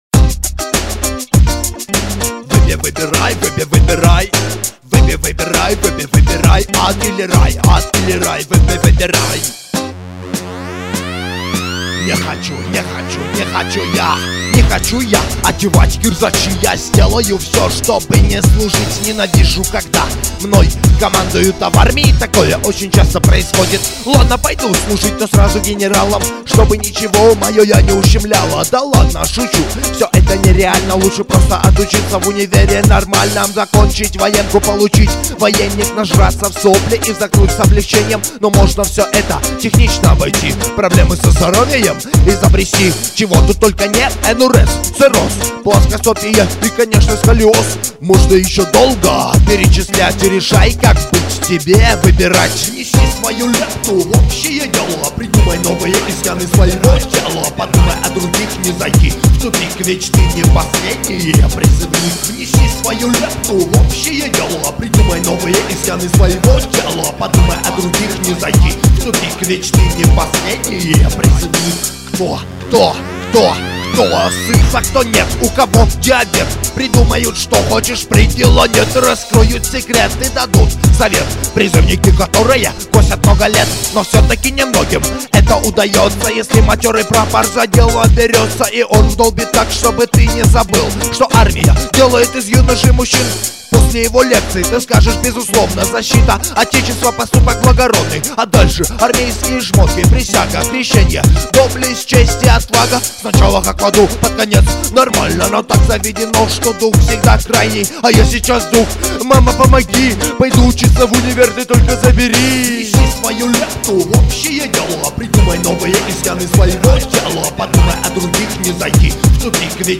undeground rap
Армейский Рэп